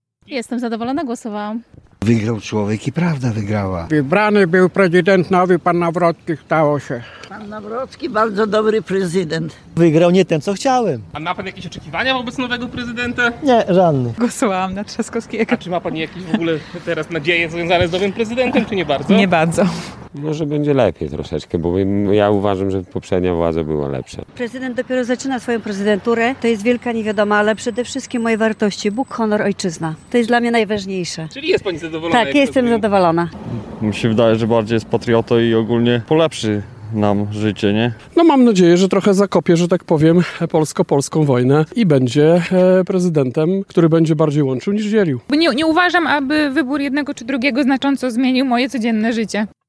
Sonda: Czego mieszkańcy Łomży oczekują od nowego prezydenta?
Spytaliśmy mieszkańców Łomży jak oceniają wynik wyborów oraz czego oczekują od przyszłej głowy państwa.